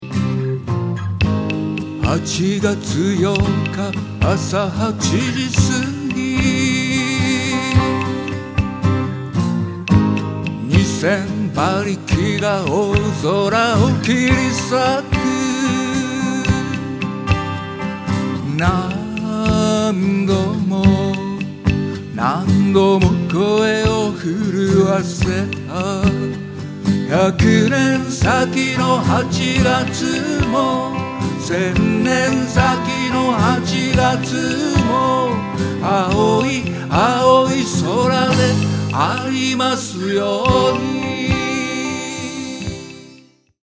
Percussion / Cajon / Cho.
Vo. / A.Guitar
A.Guitar / E.Guitar / Cho.